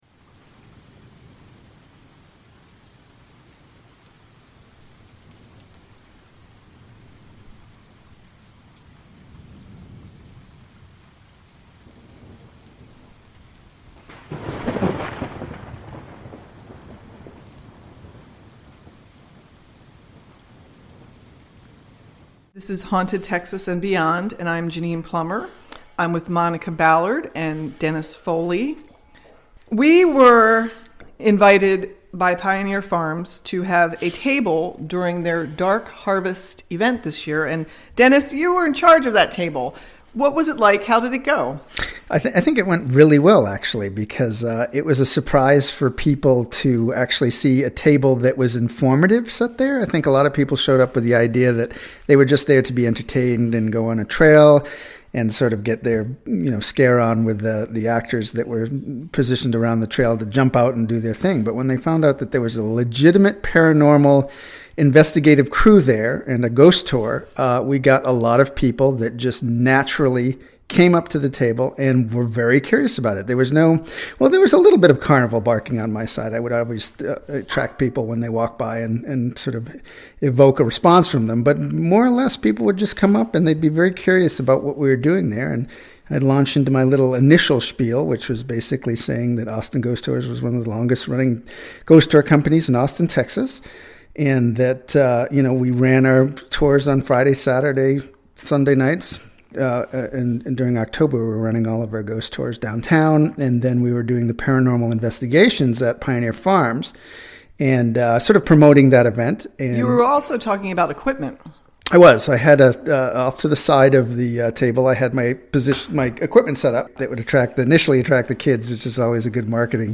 Listen to our two podcast episodes of evidence we have acquired during our 2 investigations we have there each month.